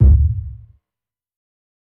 KICK_TURNK.wav